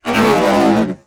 ALIEN_Communication_25_mono.wav